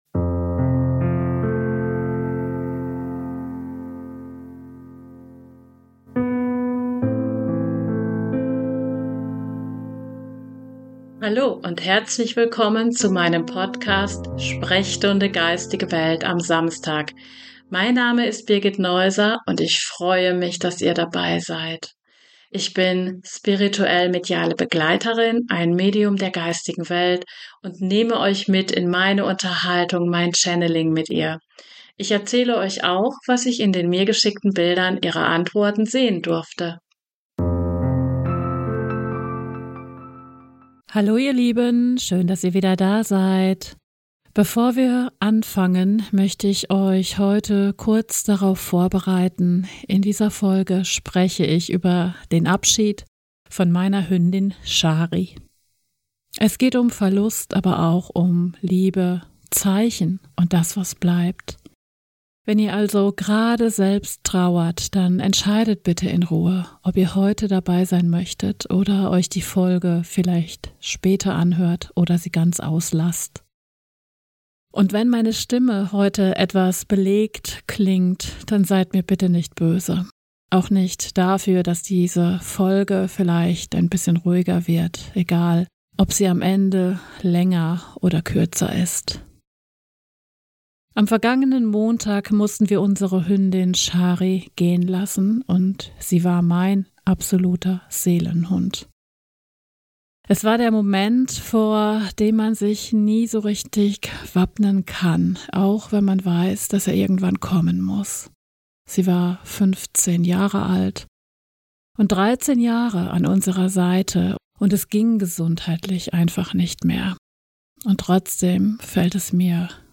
Eine ehrliche, stille und zugleich hoffentlich tröstende Folge – für alle, die schon einmal ein Tier verloren haben, vor der Entscheidung stehen, es gehen lassen zu müssen, oder sich fragen, ob Liebe wirklich bleibt.